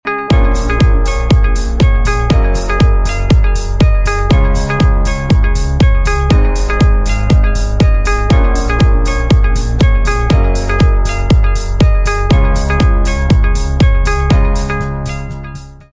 ABS/PLA 3D Printer Filament Extrusion sound effects free download